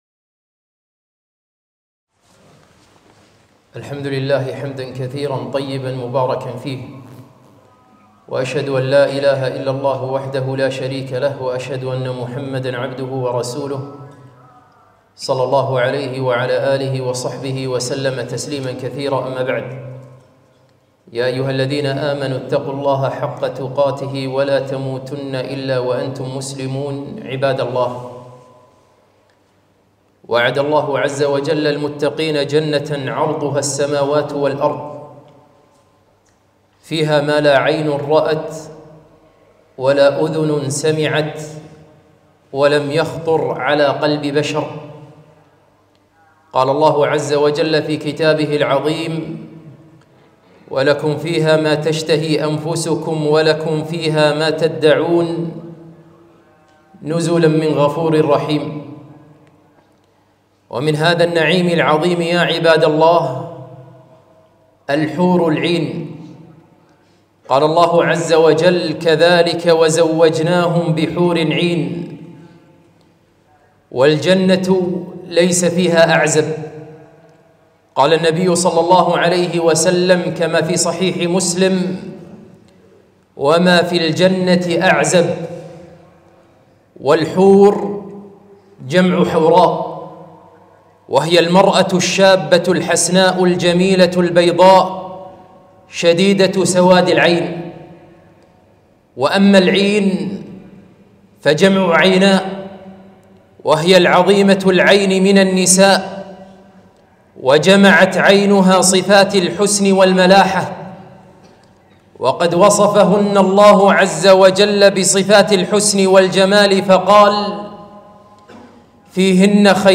خطبة - يا خاطب الحور العين